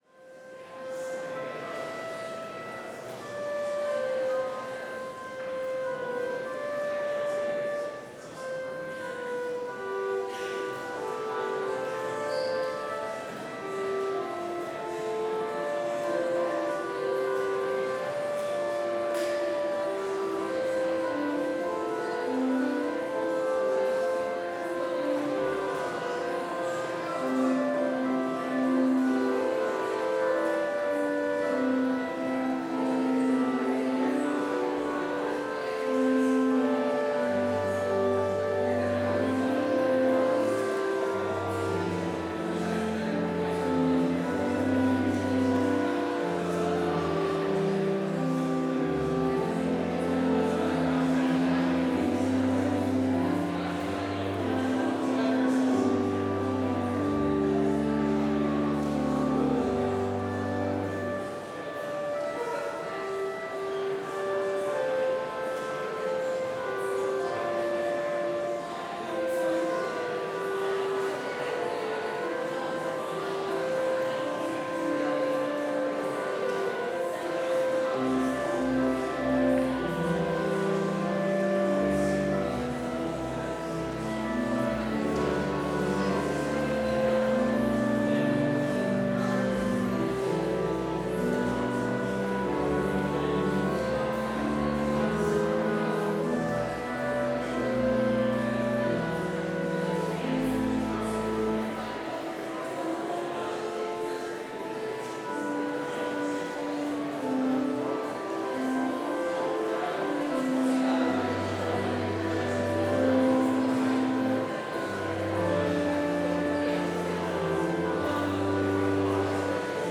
Complete service audio for Chapel - Tuesday, August 26, 2025